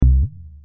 Virus_SubBass2.mp3